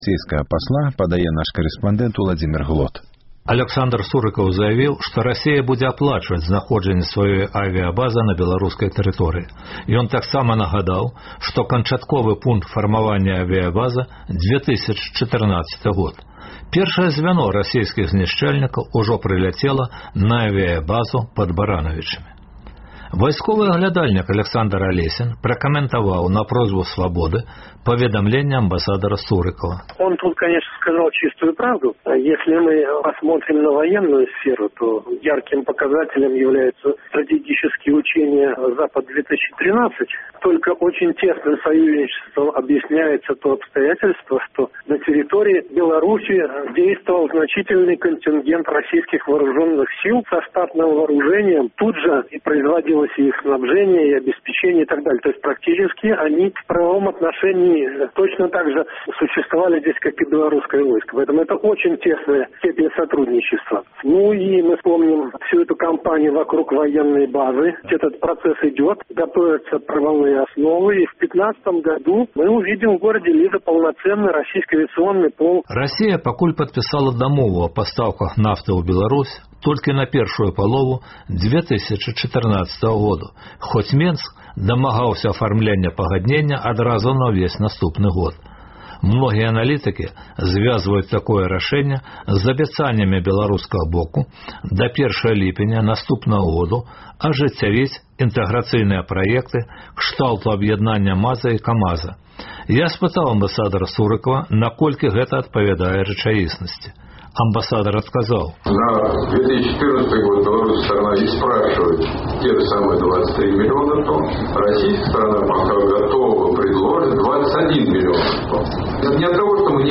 Сярод тэмаў: Рэпартаж нашага карэспандэнта зь менскіх вуліц.